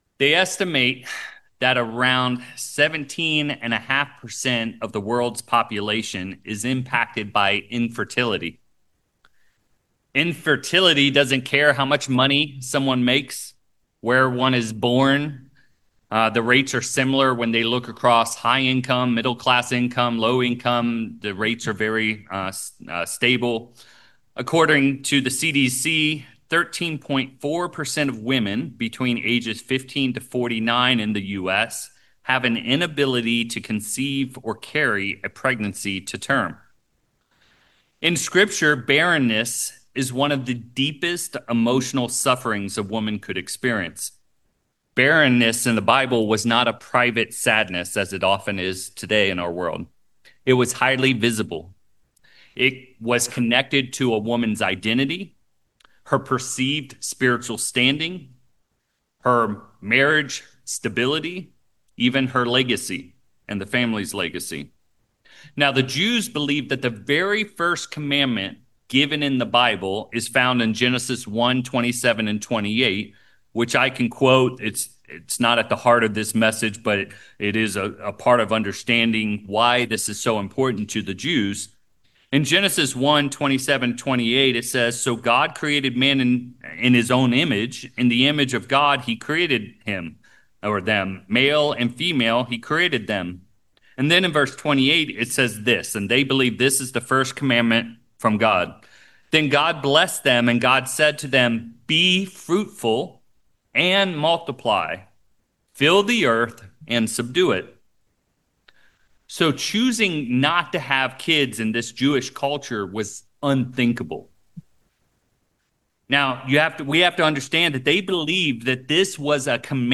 This sermon explores how God works through seasons of barrenness to reveal His timing, purpose, and power. Drawing from the stories of Hannah and Elizabeth, the message shows that barrenness is not evidence of God’s absence but the very space where He often performs His greatest work.